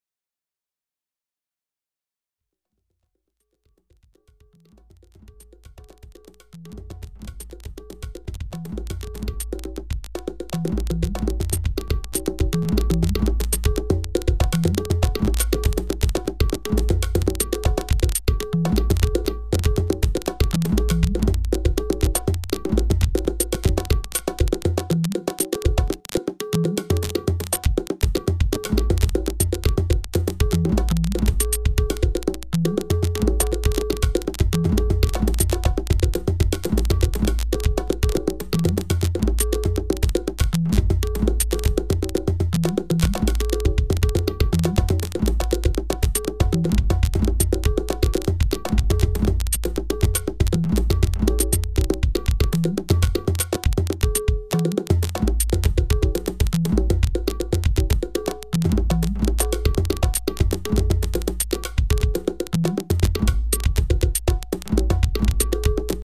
ジャズ、ワールド